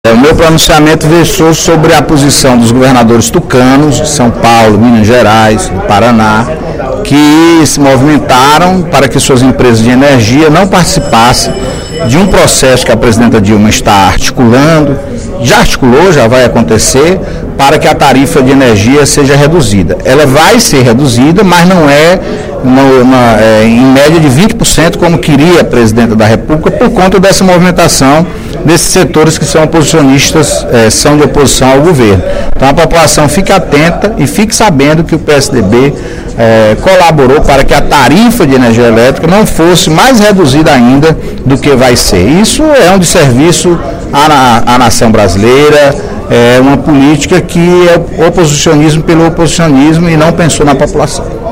O deputado Antonio Carlos (PT) afirmou, nesta quarta-feira (05/12), durante o primeiro expediente, que a promessa de redução em 20% na tarifa de energia para consumidores e empresas a partir de 2013 não vai se concretizar neste percentual devido a “uma movimentação” de setores de oposição ao Governo Federal.